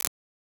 Abstract Click (5).wav